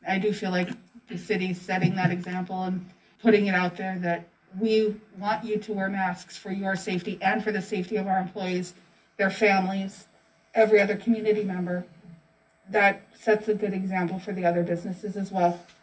Mobridge City Council discusses mask policies